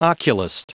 Prononciation du mot oculist en anglais (fichier audio)
Prononciation du mot : oculist